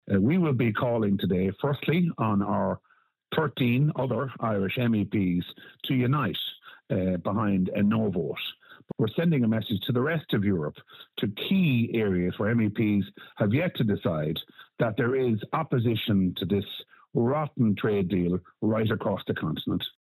North West MEP Ciaran Mullooly will be in attendance and he says the protest will send a message to Europe: